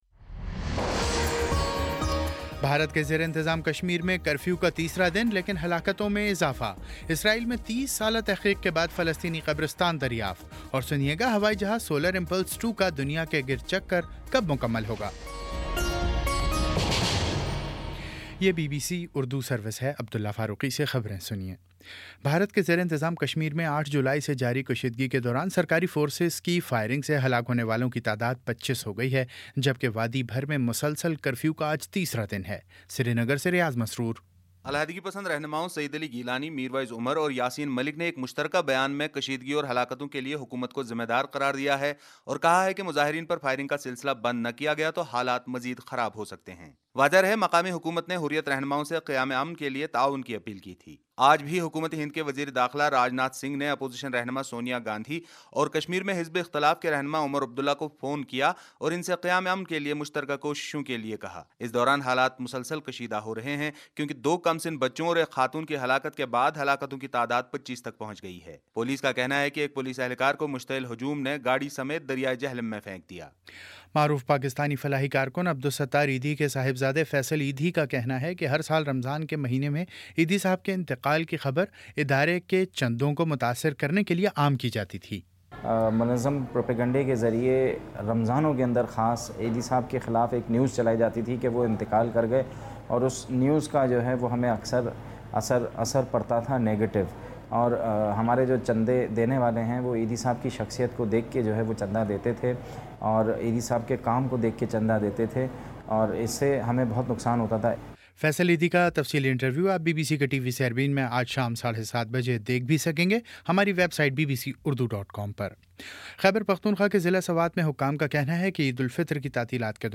جولائی 11 : شام چھ بجے کا نیوز بُلیٹن